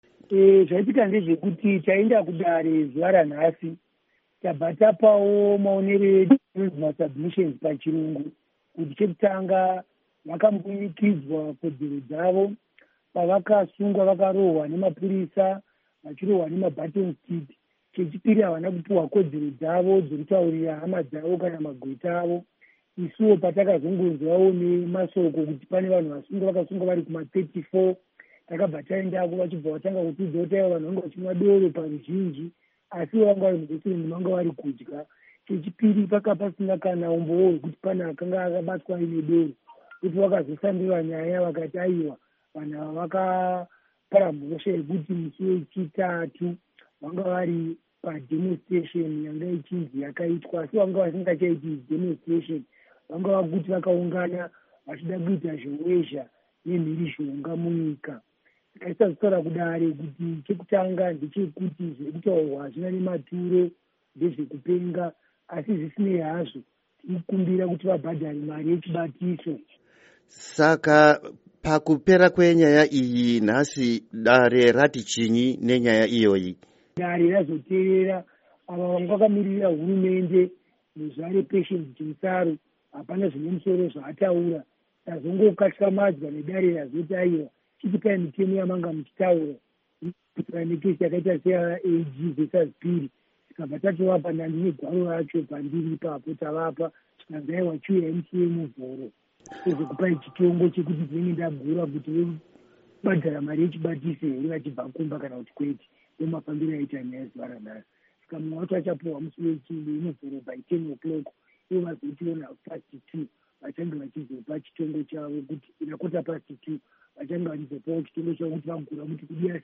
Hurukuro naVaInnocent Gonese